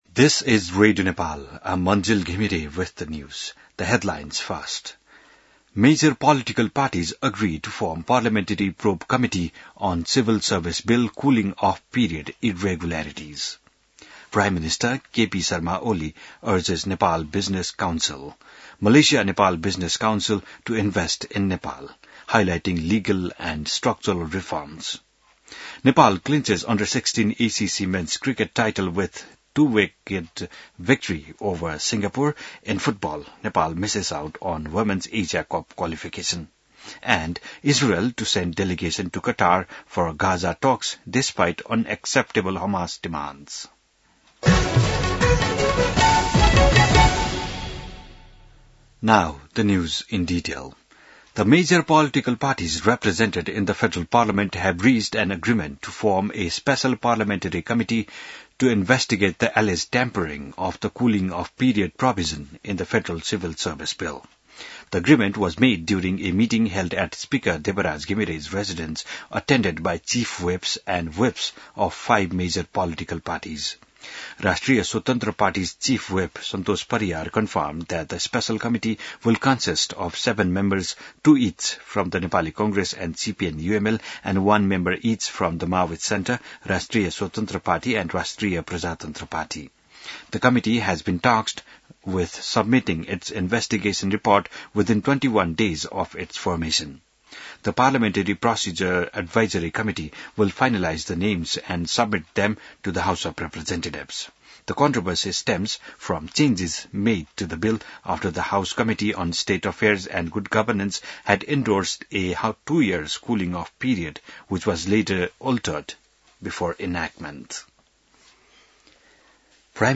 बिहान ८ बजेको अङ्ग्रेजी समाचार : २२ असार , २०८२